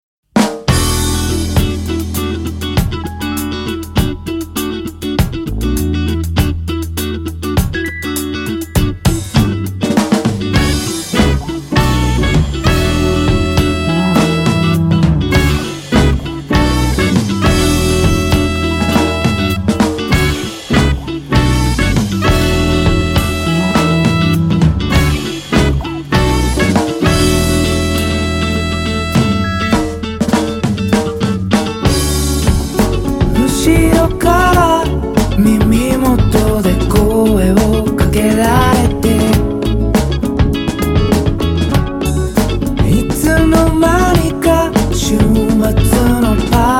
Singer-songwriter
funk band